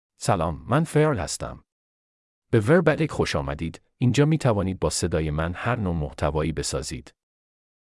Farid — Male Persian AI voice
Farid is a male AI voice for Persian (Iran).
Voice sample
Male
Farid delivers clear pronunciation with authentic Iran Persian intonation, making your content sound professionally produced.